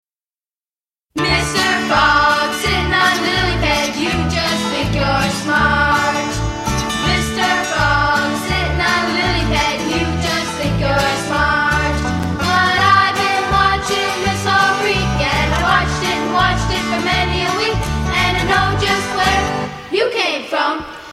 Children's Song About Frogs